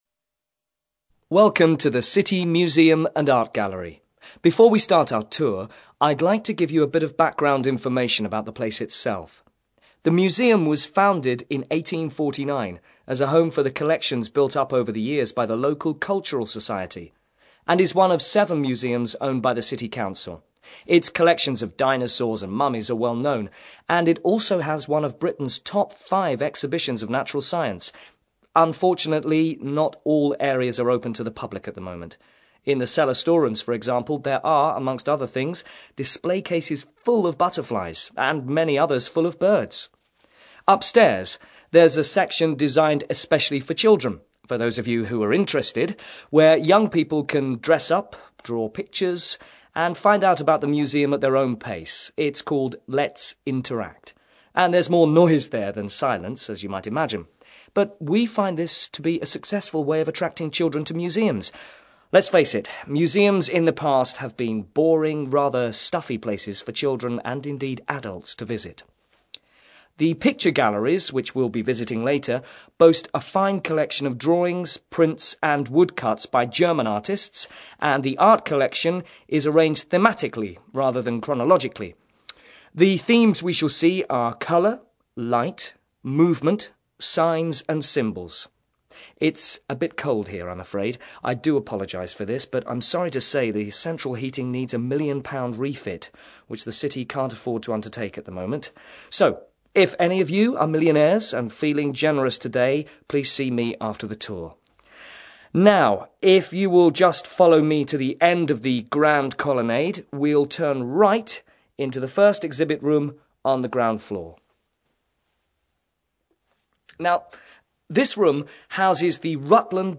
ACTIVITY 94: You'll hear a guide taking a group of visitors around a museum.